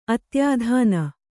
♪ atyādhān a